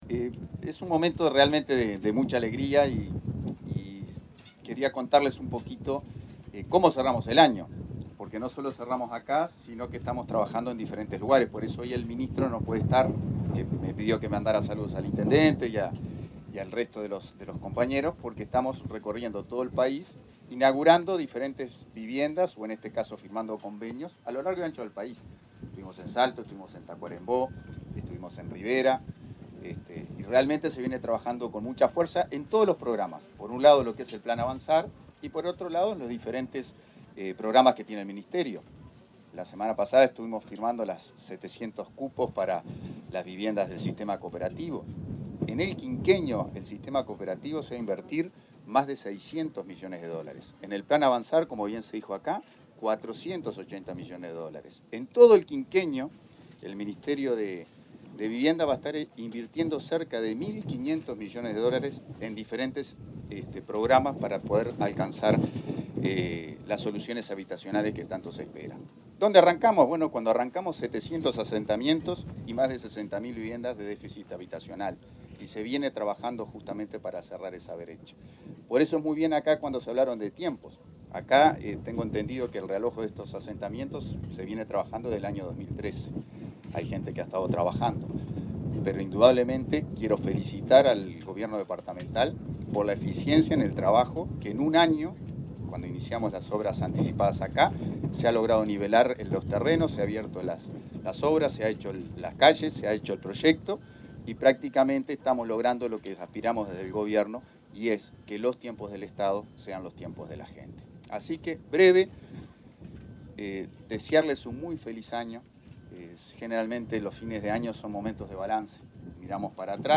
Palabras de autoridades del Ministerio de Vivienda en Minas
Palabras de autoridades del Ministerio de Vivienda en Minas 29/12/2023 Compartir Facebook X Copiar enlace WhatsApp LinkedIn El subsecretario de Vivienda, Tabaré Hackembruch, y la directora nacional de Integración Social y Urbana, Florencia Arbeleche, participaron, este viernes 29 en Minas, Lavalleja, del lanzamiento de obras del plan Avanzar.